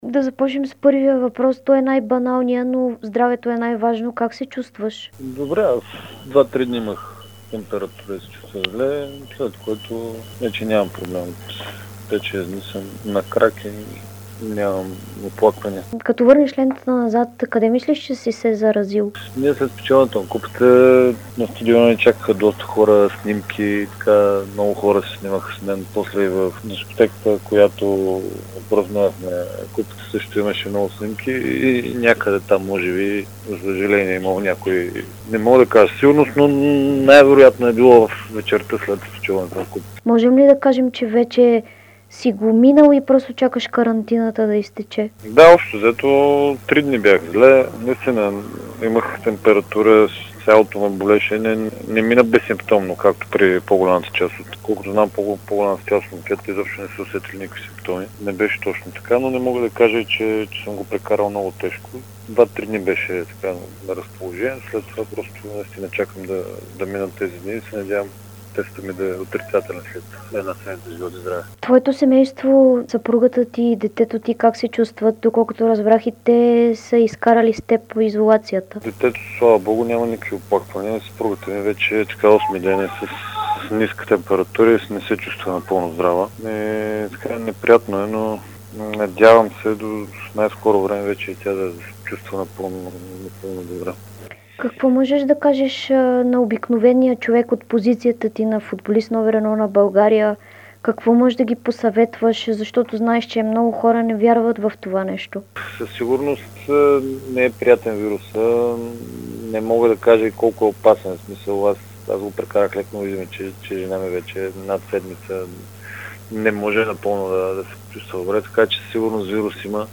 Футболист №1 на България за 2019 година Димитър Илиев даде интервю за dsport и Дарик радио от дома си, където той и цялото му семейство прекарват карантината, след като дадоха положителни проби за коронавирус.